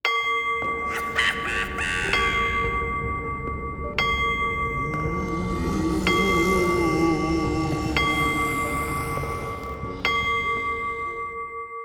cuckoo-clock-06.wav